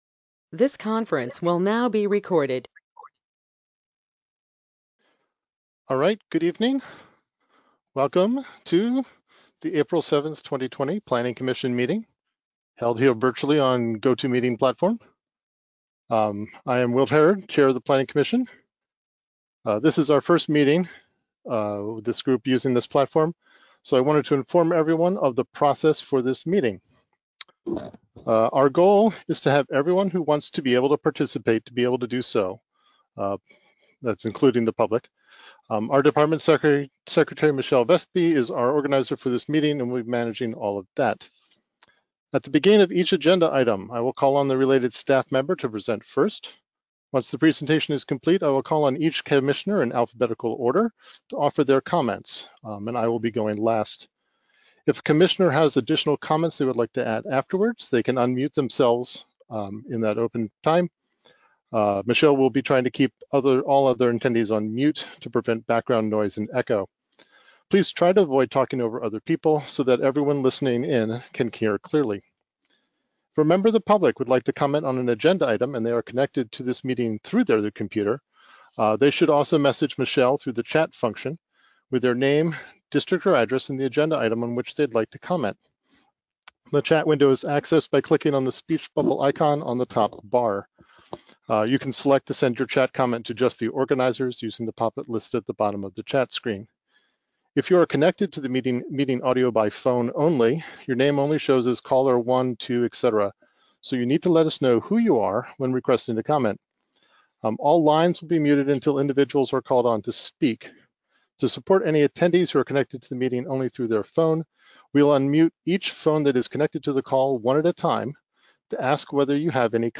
Listen to the audio from the April 7, 2020 Planning Commission meeting here.